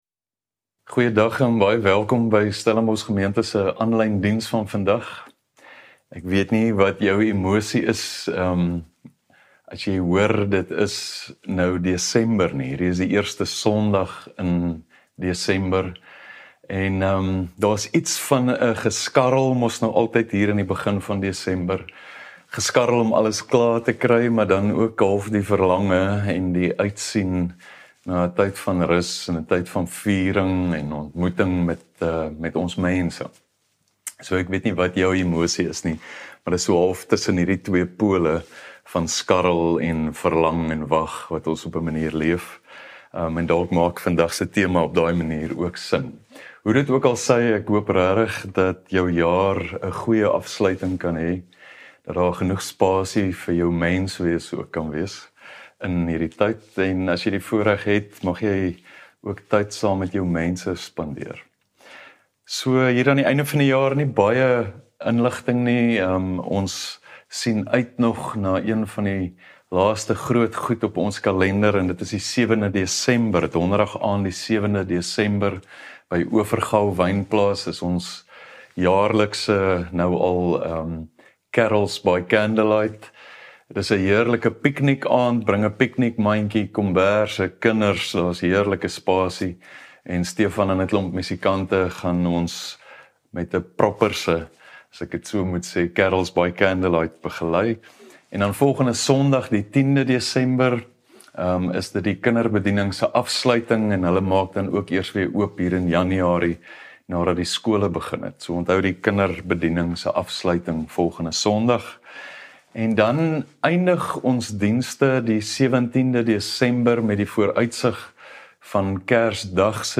Stellenbosch Gemeente Preke 03 Desember 2023 || Waarvoor wag jy?